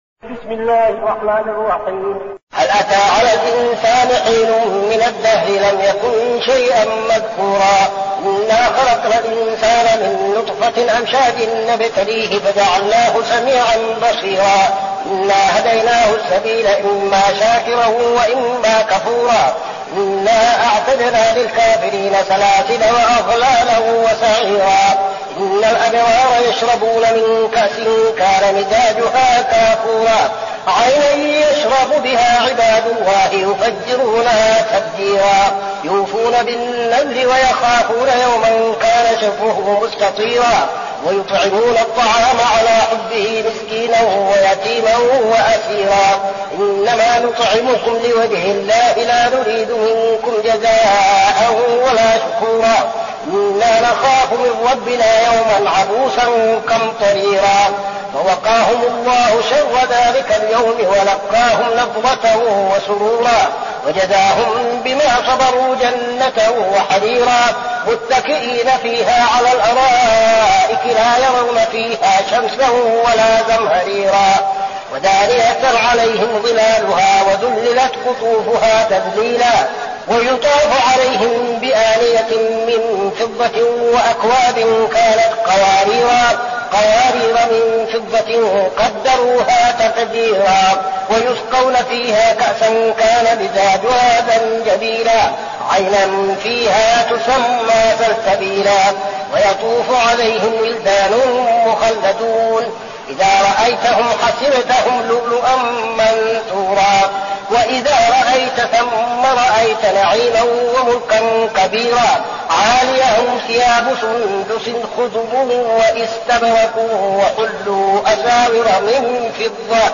المكان: المسجد النبوي الشيخ: فضيلة الشيخ عبدالعزيز بن صالح فضيلة الشيخ عبدالعزيز بن صالح الإنسان